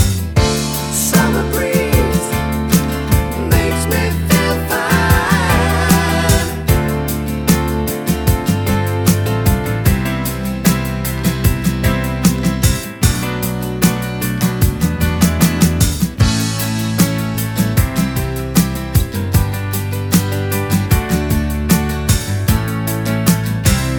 No Lead Guitar Or Solo Soul / Motown 3:21 Buy £1.50